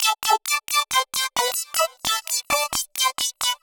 Index of /musicradar/uk-garage-samples/132bpm Lines n Loops/Synths